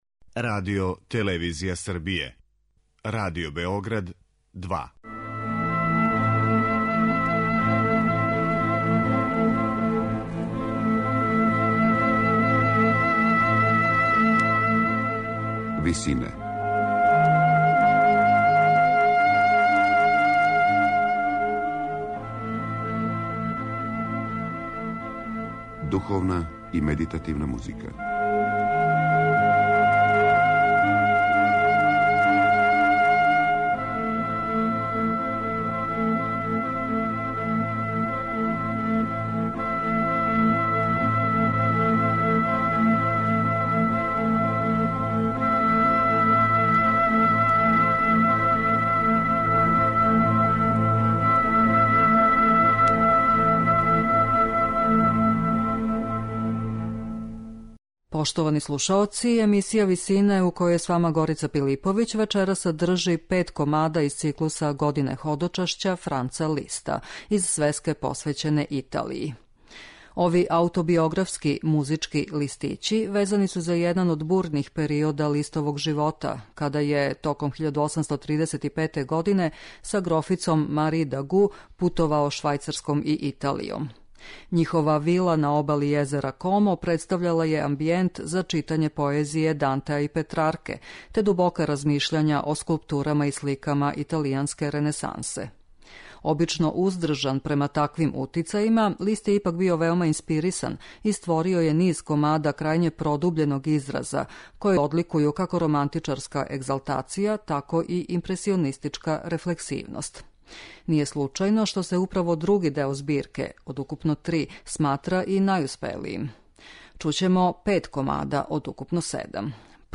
испуниће део чувене збирке клавирских комада Године ходочашћa Франца Листа.